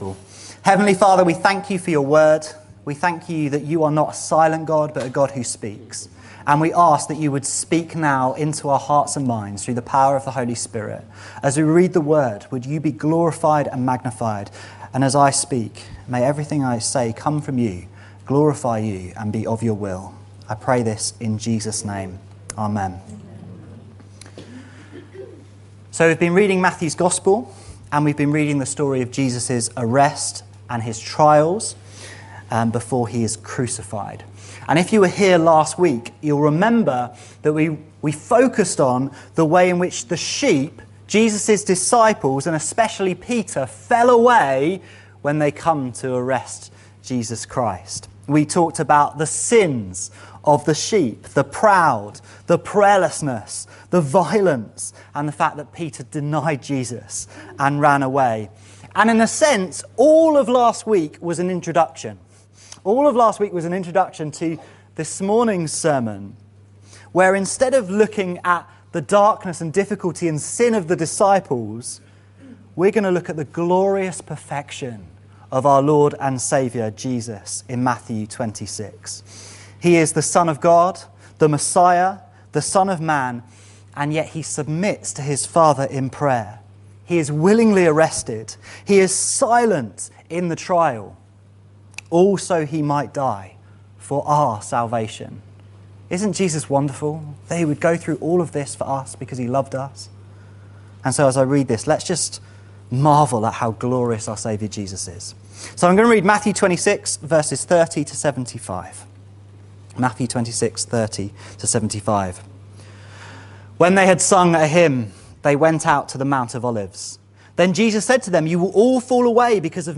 This sermon looks at these verses from the perspective of Jesus.